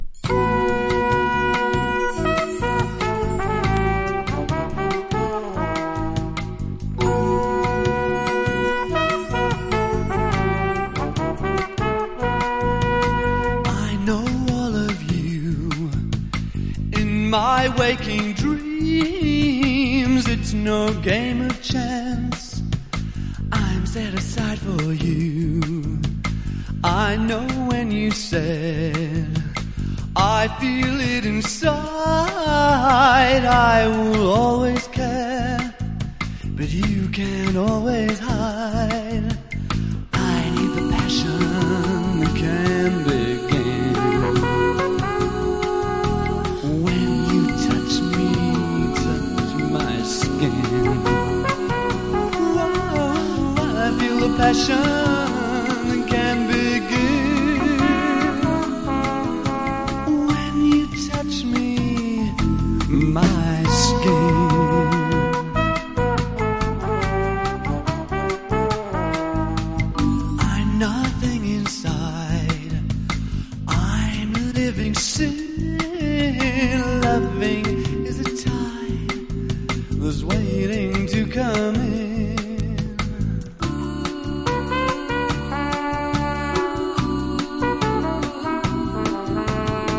リムショット連発のドラミングにホーンが入りコーラスが絶妙にマッチする気持ちいい
# NEO ACOUSTIC / GUITAR POP# NEW WAVE# 80’s ROCK / POPS